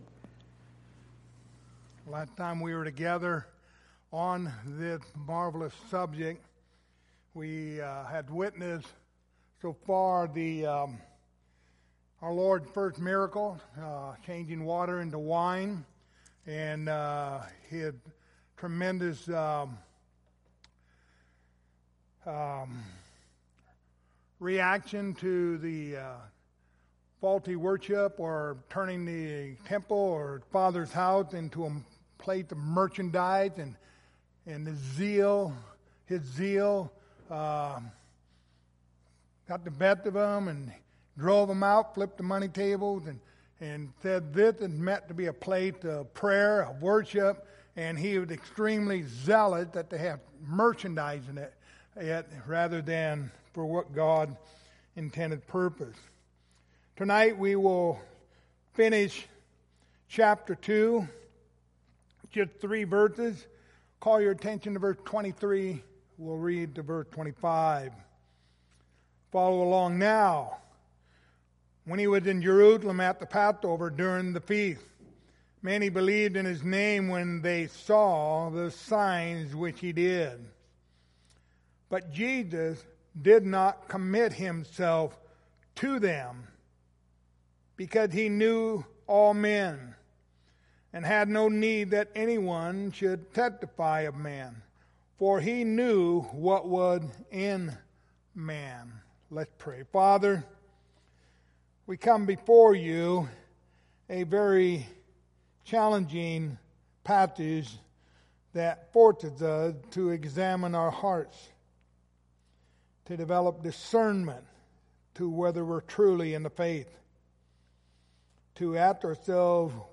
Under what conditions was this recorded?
The Gospel of John Passage: John 2:23-25 Service Type: Wednesday Evening Topics